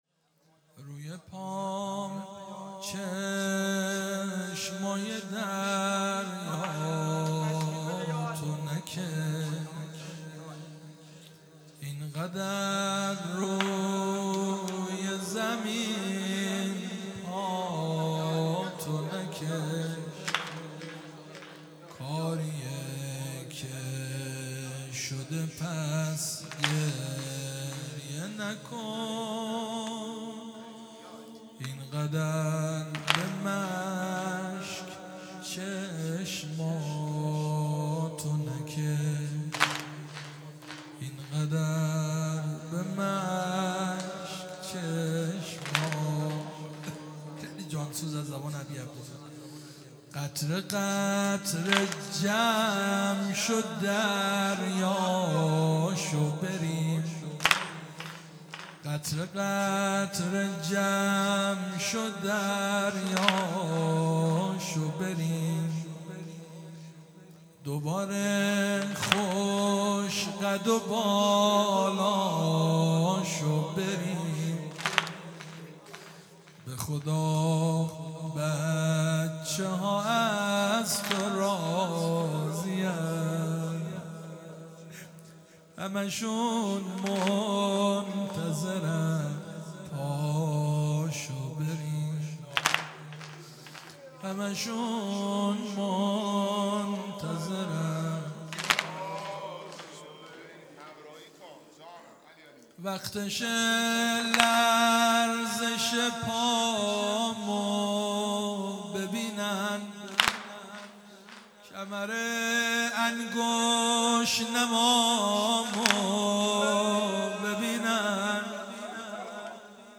شهادت امام حسن مجتبی (ع) | ۱۷ آبان ۱۳۹۵